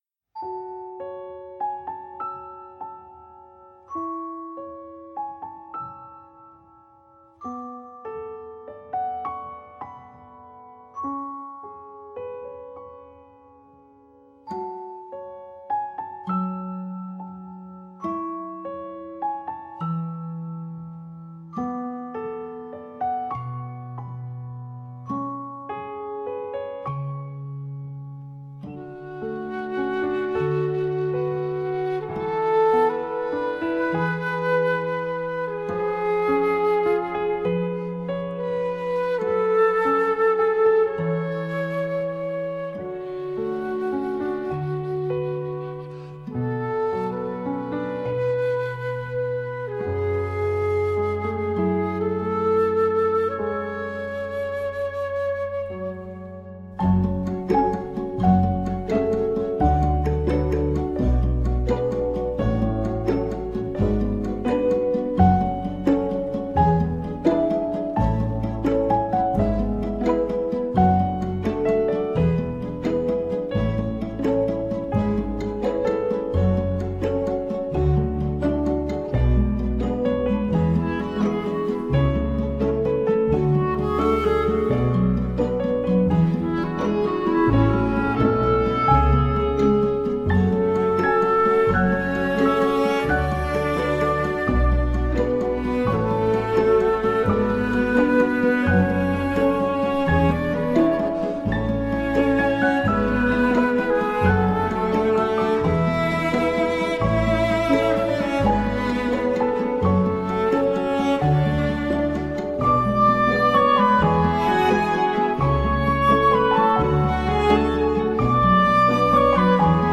ajoute une touche de suspense bienvenue